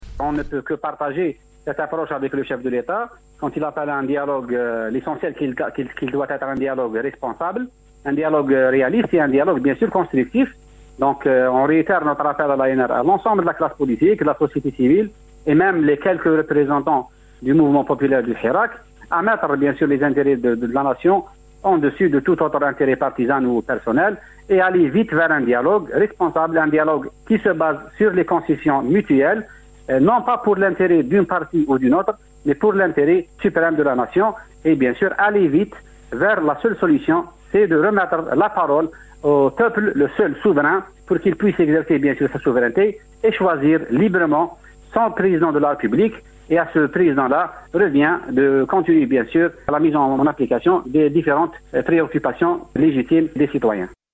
Déclaration du premier secrétaire de l'ANR